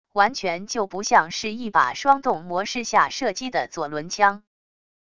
完全就不像是一把双动模式下射击的左轮枪wav音频